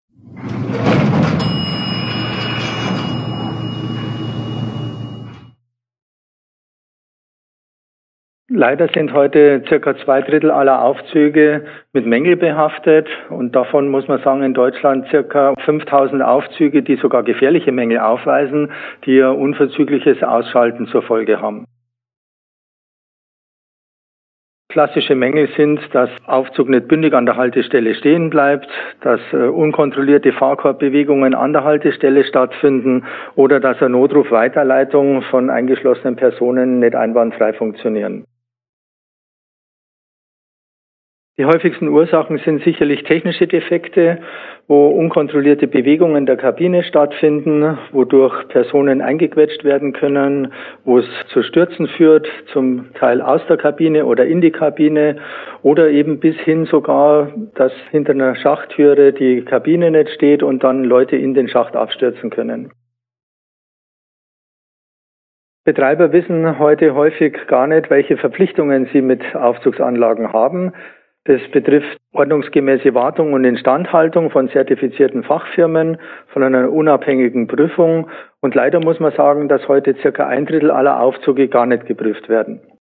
VdTÜV O-Töne Aufzugssicherheit